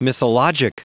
Prononciation du mot mythologic en anglais (fichier audio)
Vous êtes ici : Cours d'anglais > Outils | Audio/Vidéo > Lire un mot à haute voix > Lire le mot mythologic